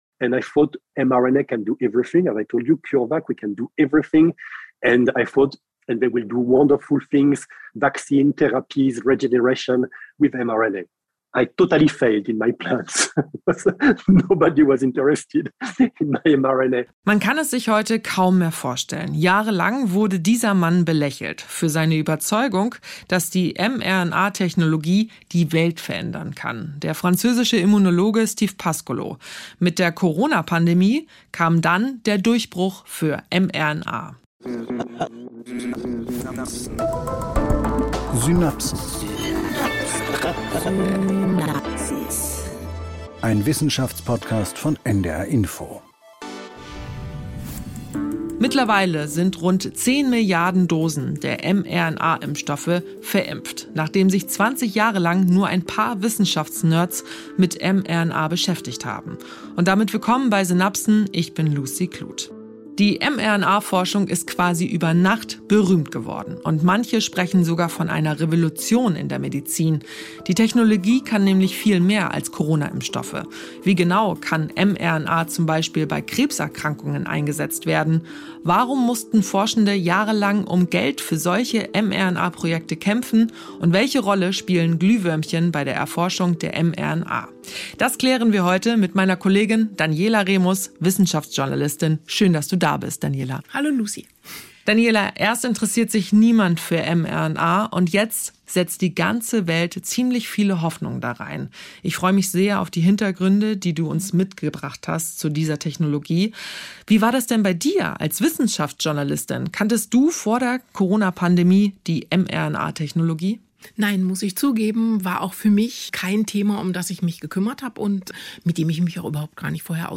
Nachrichten - 17.06.2022